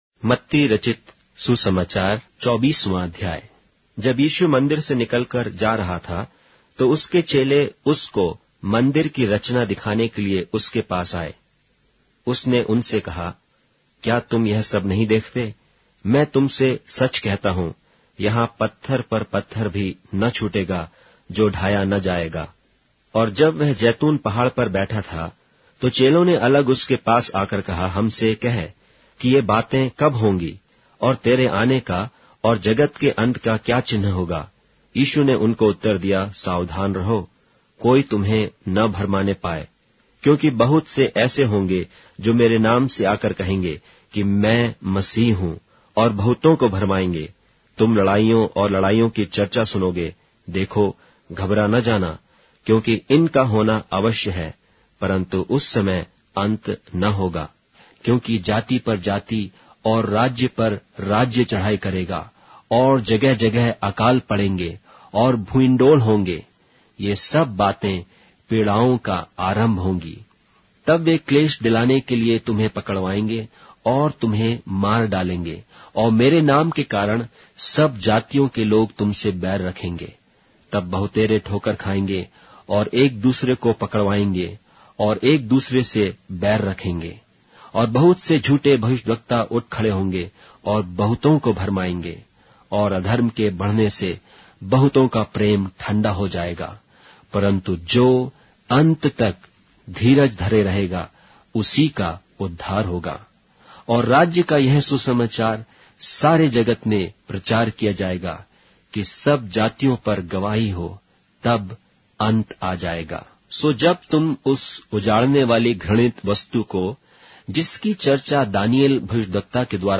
Hindi Audio Bible - Matthew 6 in Bhs bible version